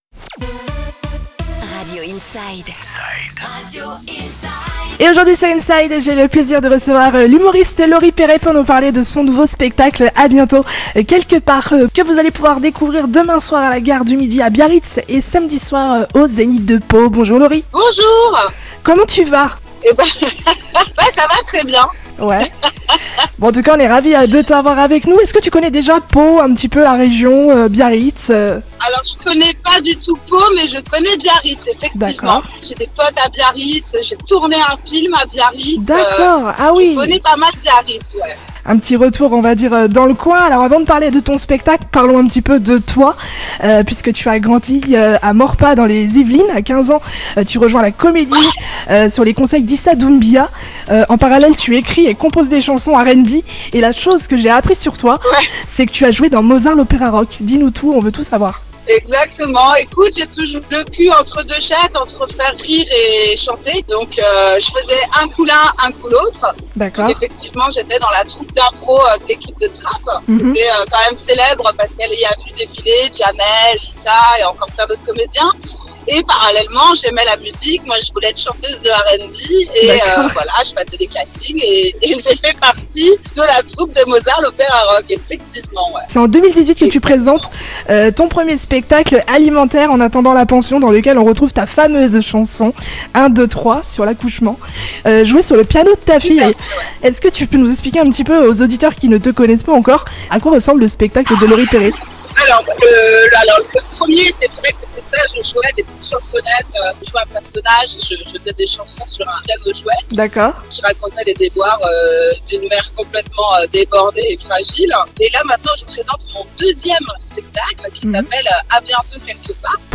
Interview de Laurie Peret " À bientôt quelque part" sur Radio Inside